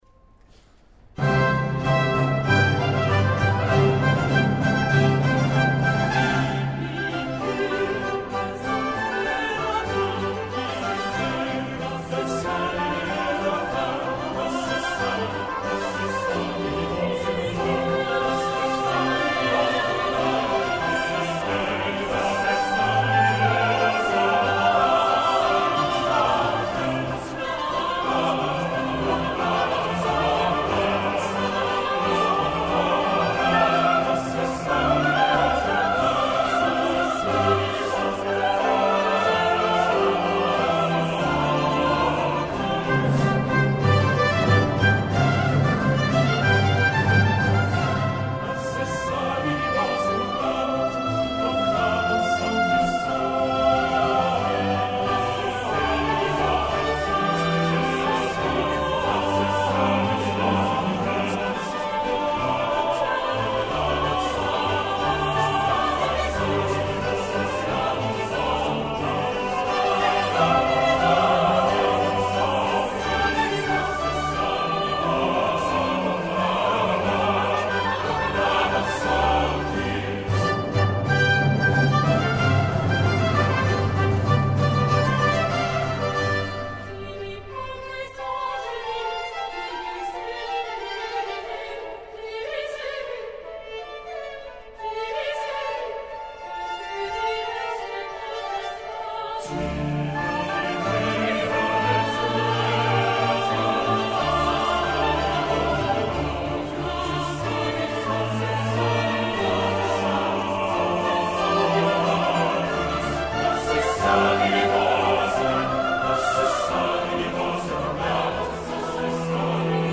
En une alternance de récits et de choeurs (petits et grands) ce Te Deum déploie ses splendeurs pour célébrer dignement, la ferveur officielle – ou obligatoire – de Versailles. L’orchestre parvient à allier savamment majesté et légèreté (belles trompettes). Le choeur est excellent, autant dans sa forme restreinte qu’en plus grand effectif et les nombreux passages fugués sont d’une clarté et d’une lisibilité remarquables.
timbre inhabituel et touchant de haute-contre à la française qui ravit par sa souplesse dans la tessiture équivoque de l’aigu.  Le beau  motet “Diligam te, Domine” vient compléter ce superbe enregistrement réalisé, comme il se doit, en la chapelle royale de Versailles.